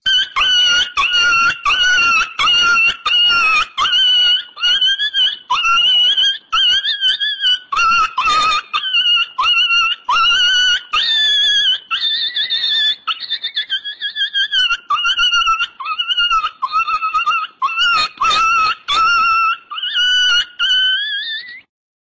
Tiếng chó bị đánh kêu MP3